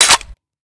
Shotgun_Malf.ogg